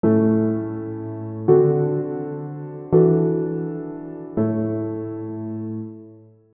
Notice how the 3rd and 5th are omitted from the E11 chord.
A - D7 - E11 - A
Chords: I (A) - IV7 (D7) - V11 (E11) - I (A)